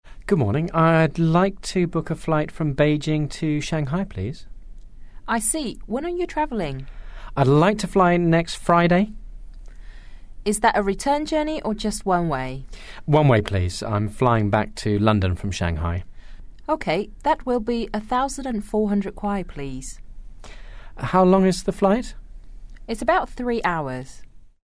英语初学者口语对话第53集：我想订从北京到上海的飞机票
english_25_dialogue_2.mp3